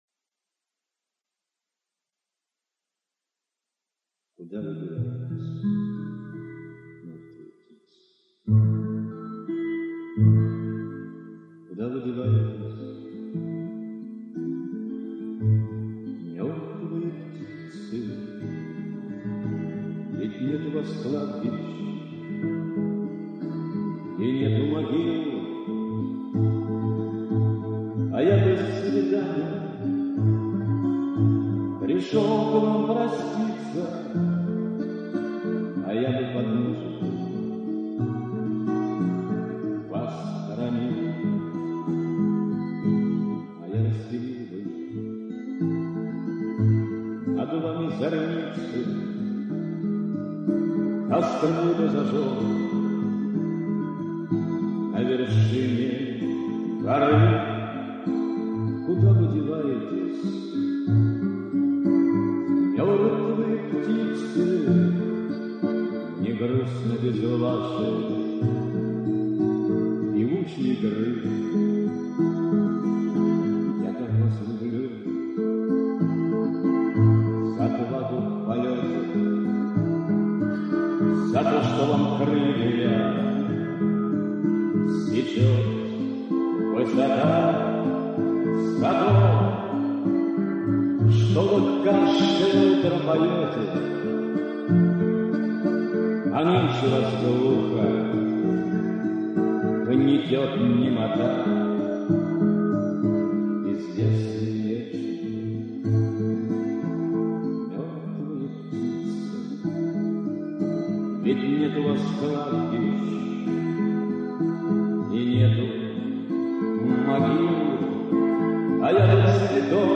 Русский бард
Исполнял песни на стихи русских поэтов.